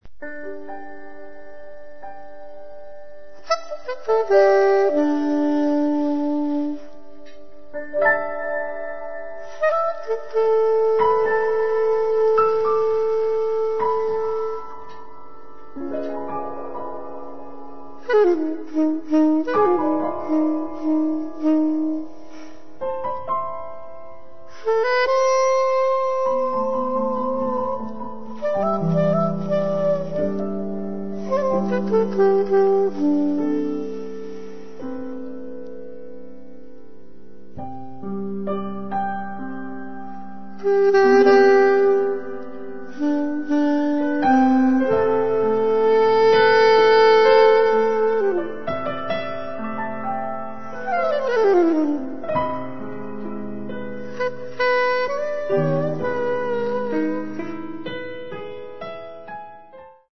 pianoforte
sassofoni